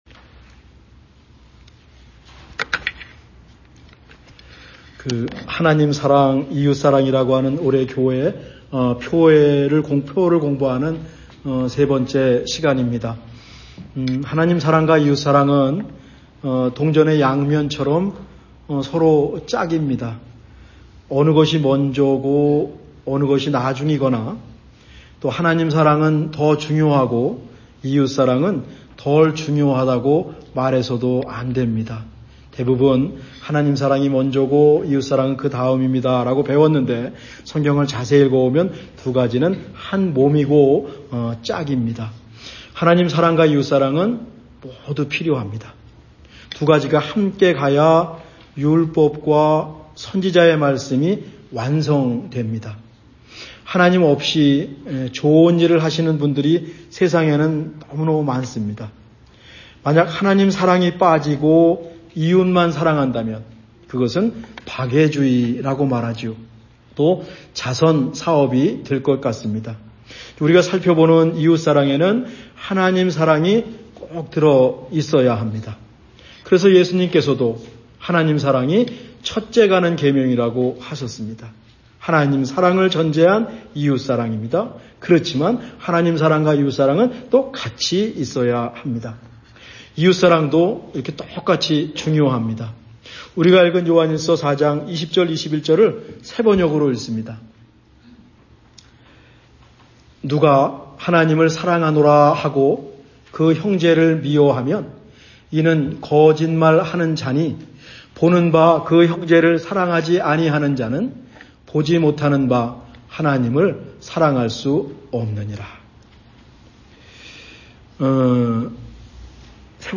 2026년 1월 3주 말씀